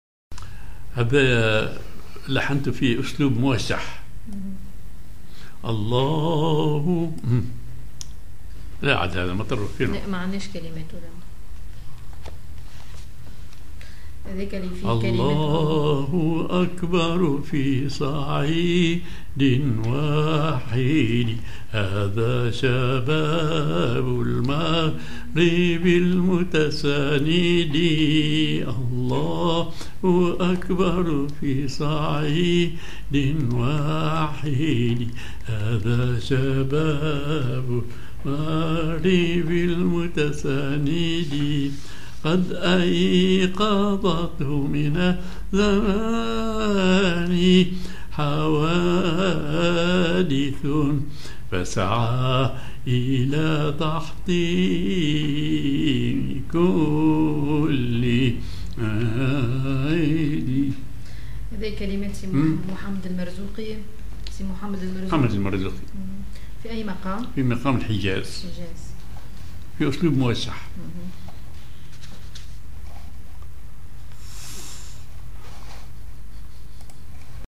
ar حجاز
ar أقصاق
موشح